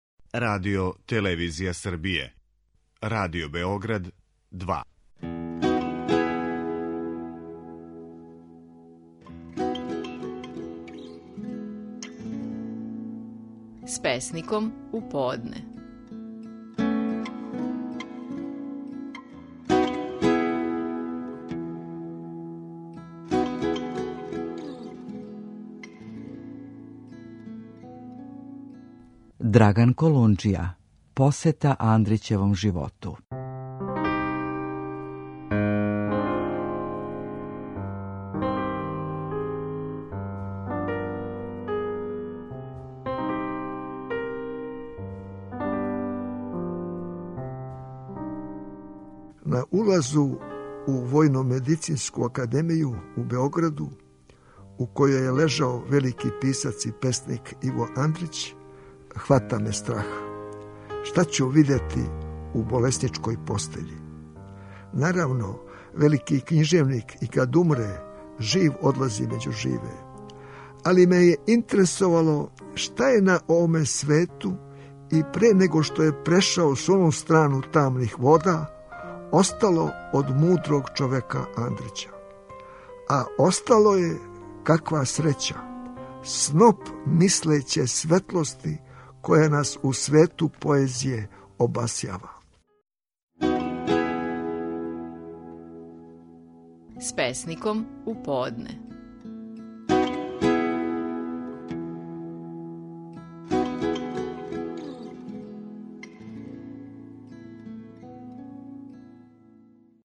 Стихови наших најпознатијих песника, у интерпретацији аутора.
Драган Колунџија говори стихове песме „Посвета Андрићевом животу".